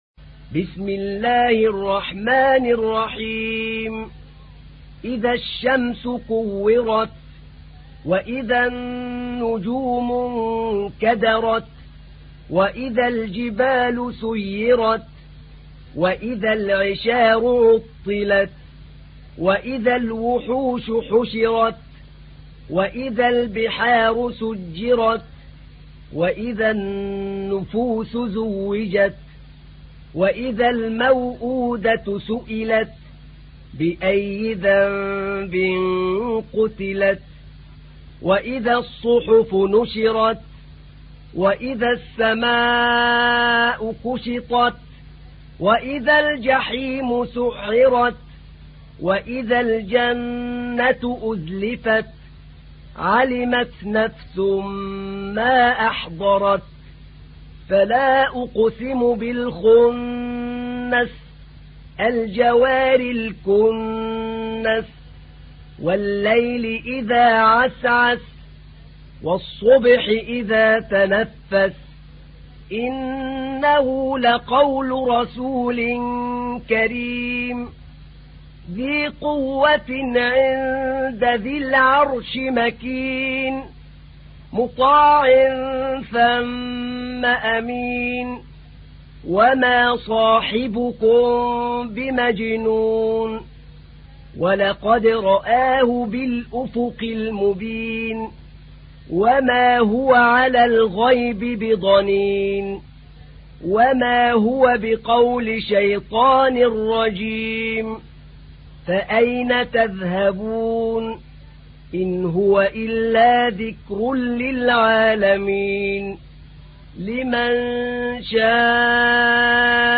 تحميل : 81. سورة التكوير / القارئ أحمد نعينع / القرآن الكريم / موقع يا حسين